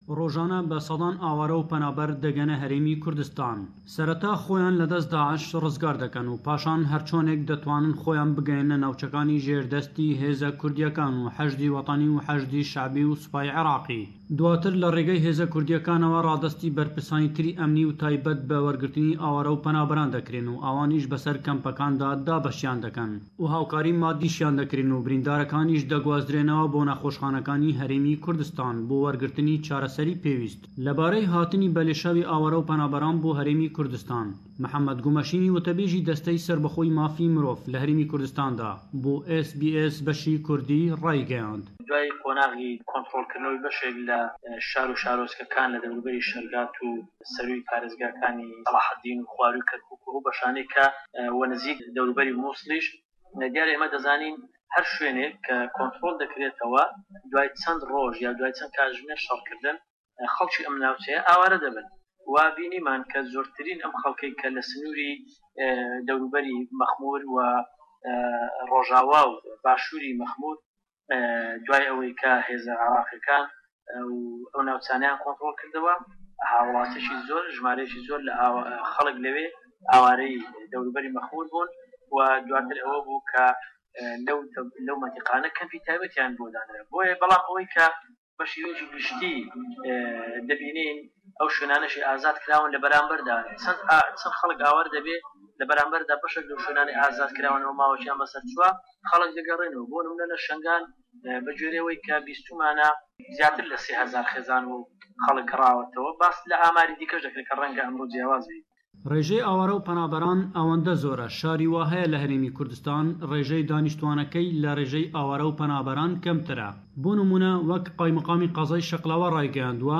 Raporteke taybet sebaret bi mijarê